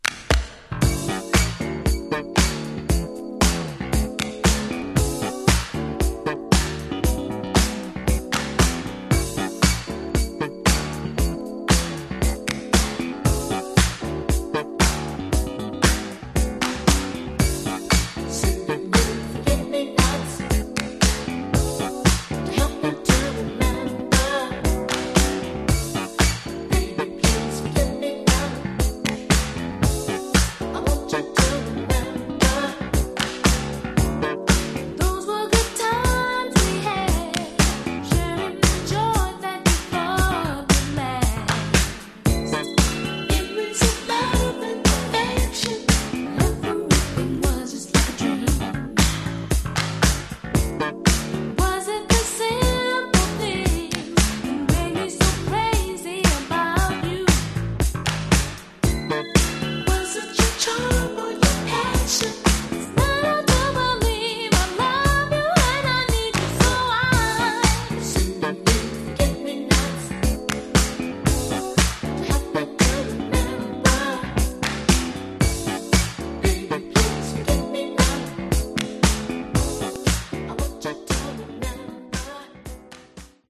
Genre: Funk/Hip-Hop/Go-Go